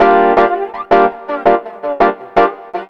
02 ChaChaa 165 F.wav